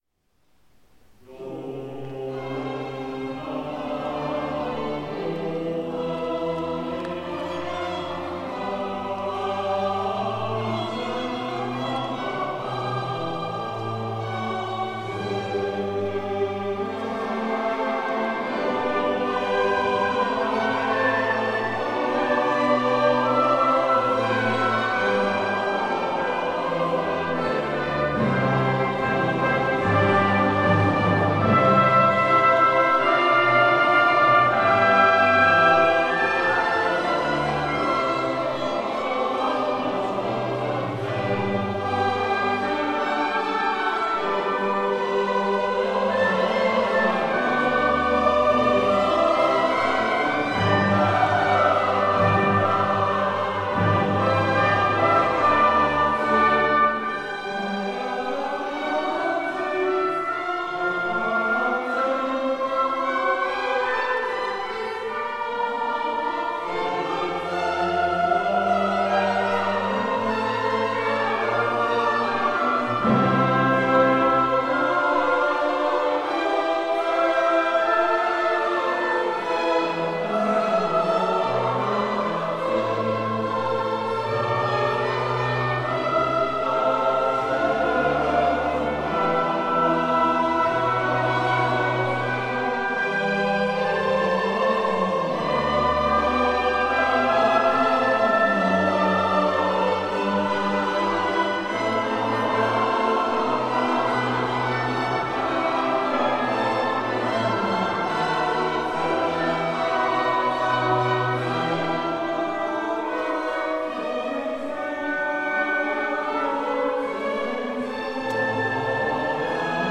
(Ostern)
Ignaz Holzbauer (1711 - 1783): Missa in C für Soli, Chor und Orchester